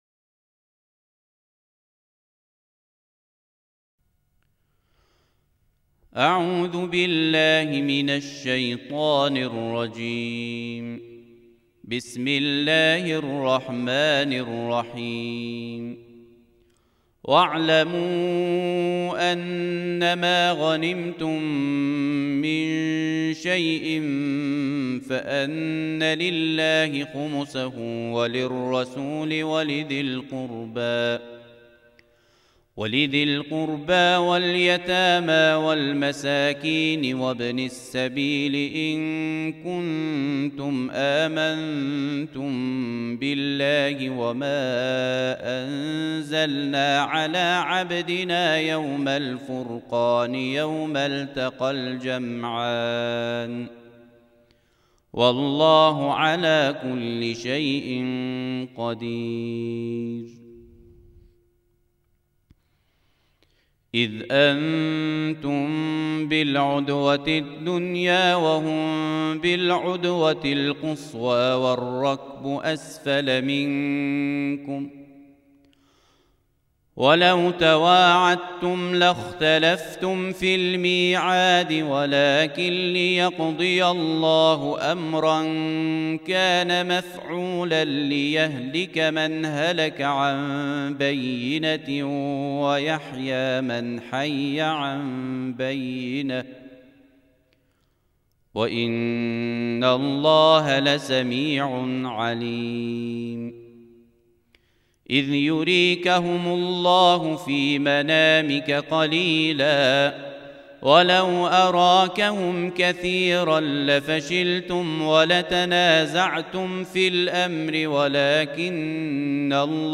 فیلم ‌| تلاوت ترتیل جزء دهم قرآن کریم